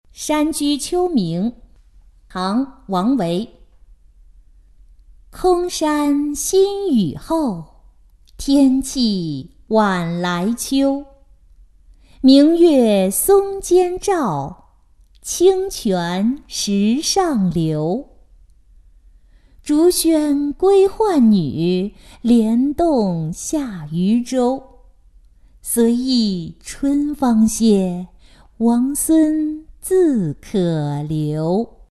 叹花-音频朗读